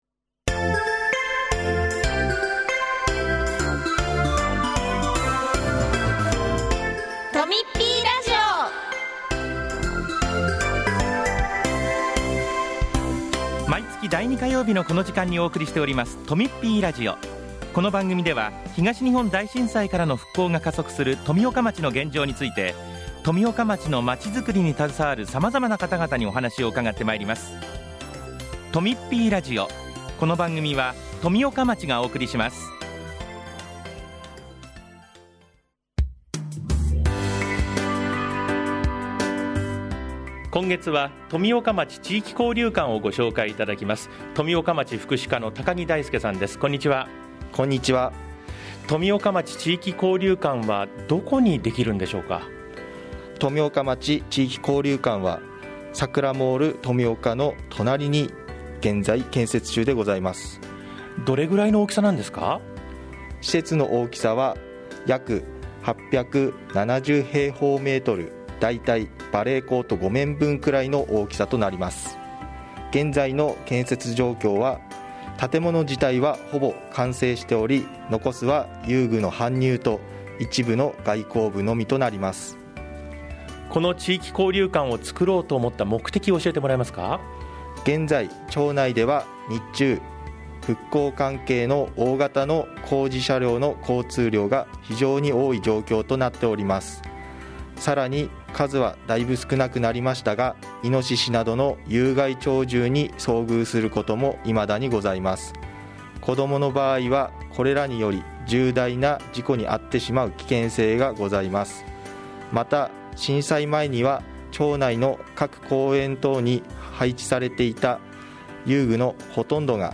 インタビューは、今年3月に開館予定の地域交流館について紹介します。